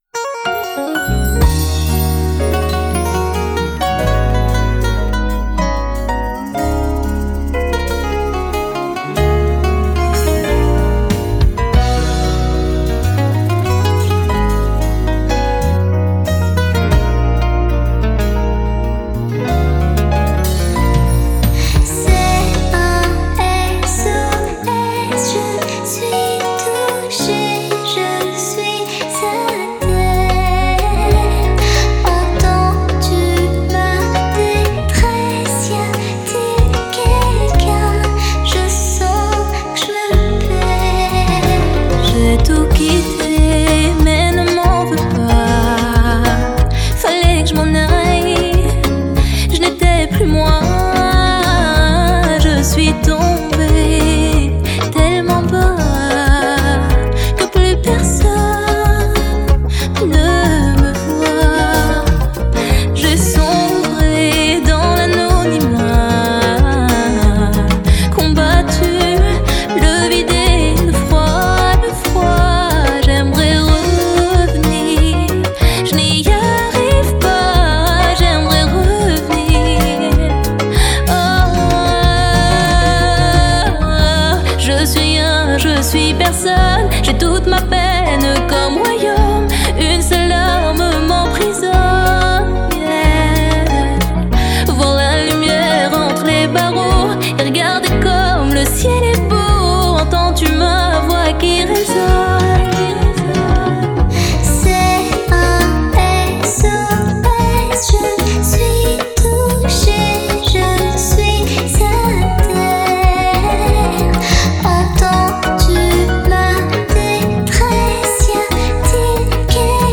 musique salsa bachata kizomba mix remix